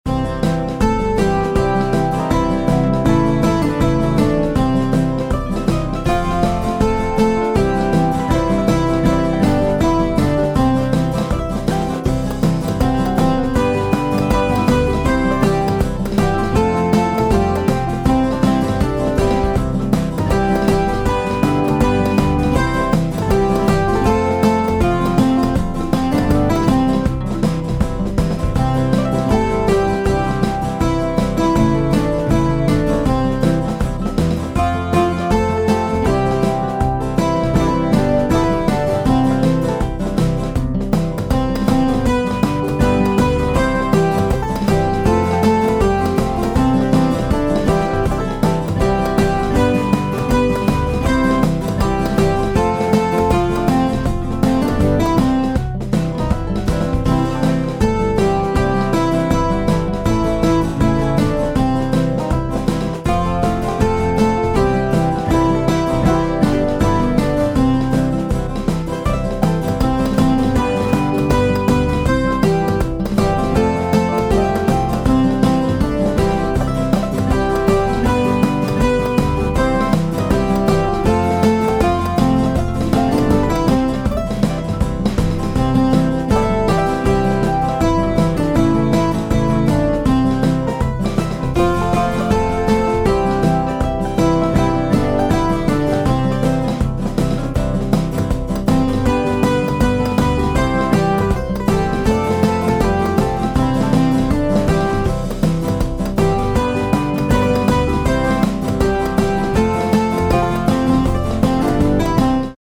midi-demo 1